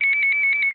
sfx_eurn_confirm.ogg